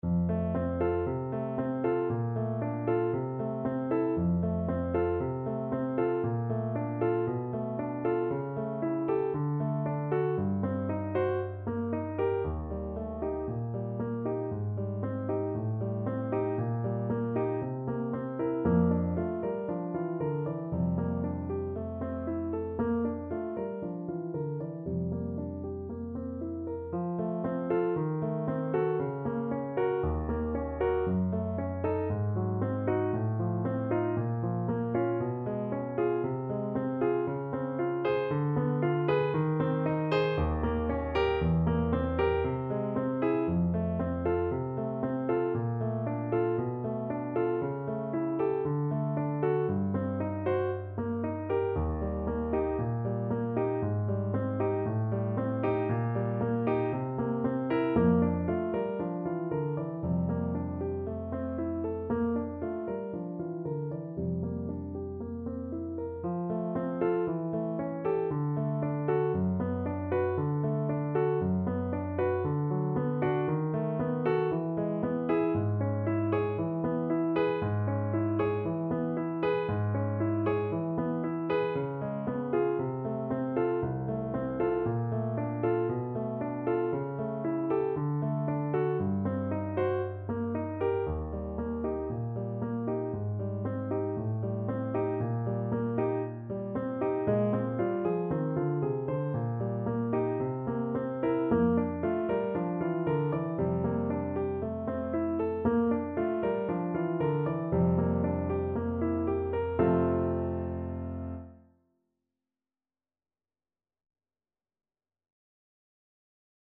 Play (or use space bar on your keyboard) Pause Music Playalong - Piano Accompaniment Playalong Band Accompaniment not yet available transpose reset tempo print settings full screen
Allegro moderato =116 (View more music marked Allegro)
Classical (View more Classical Clarinet Music)